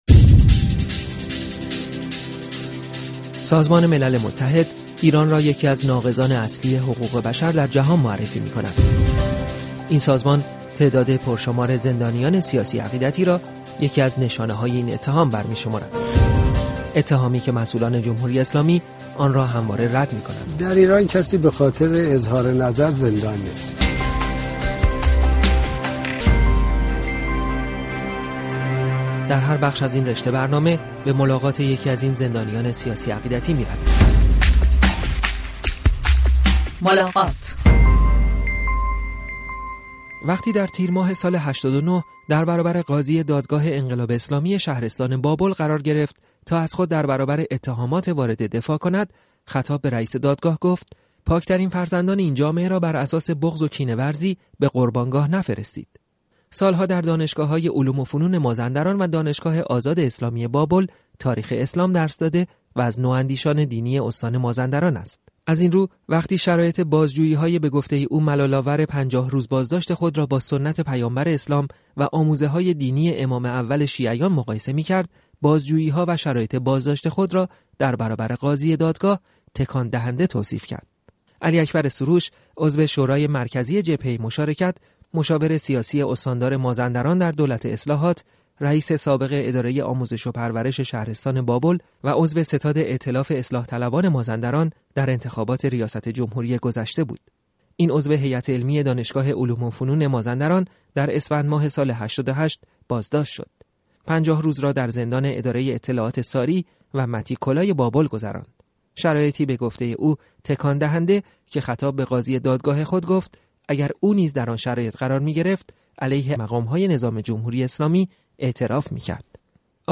«ملاقات» روایتی است کوتاه از کسانی که روزگارشان محدود به چهاردیواریی است به نام زندان.